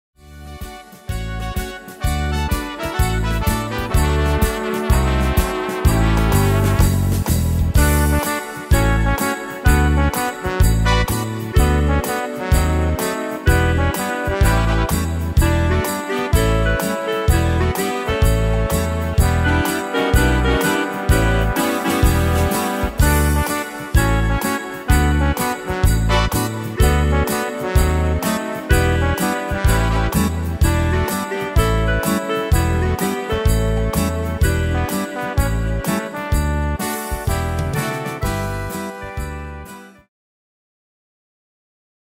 Tempo: 126 / Tonart: D-Dur